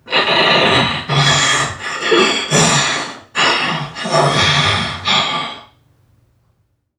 NPC_Creatures_Vocalisations_Robothead [64].wav